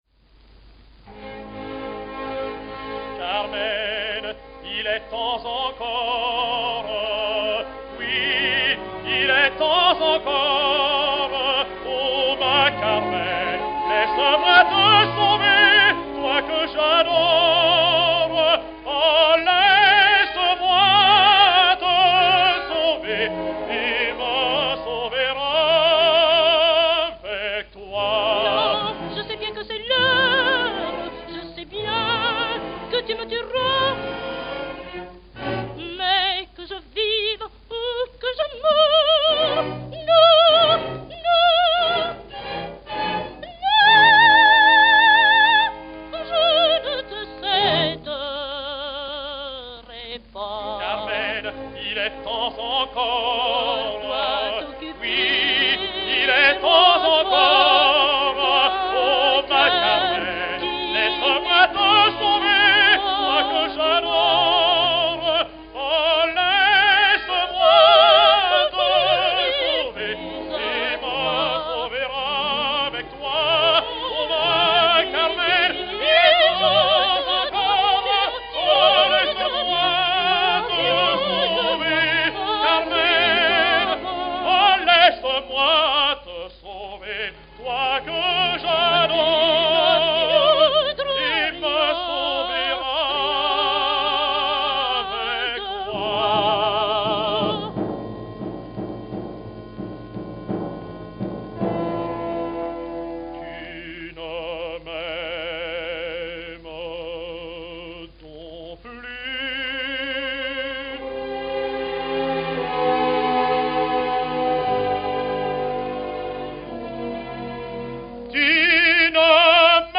Paris, France Paris, France
Note: Volume problems on master.